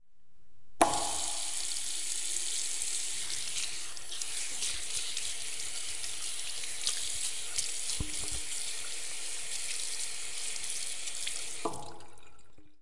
描述：这个声音是在Pompeu Fabra大学的campus de la comunicació录制的。